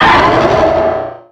Cri de Méga-Lucario dans Pokémon X et Y.
Cri_0448_Méga_XY.ogg